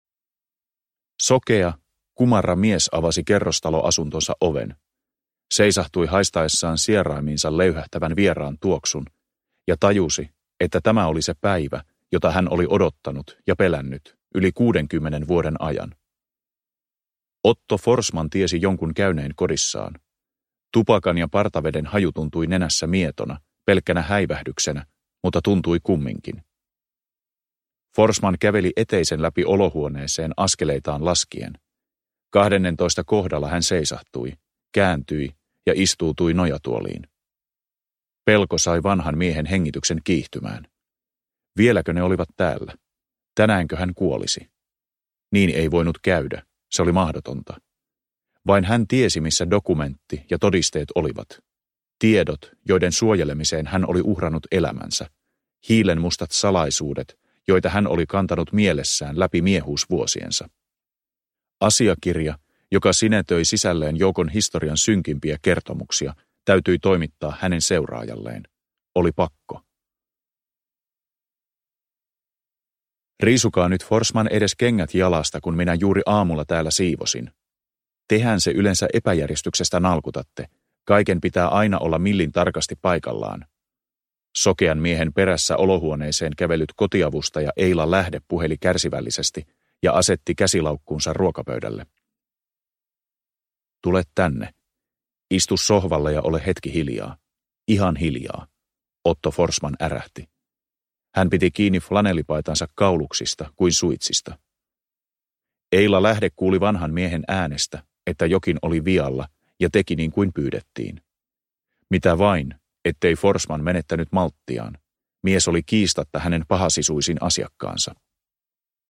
Marsalkan miekka – Ljudbok – Laddas ner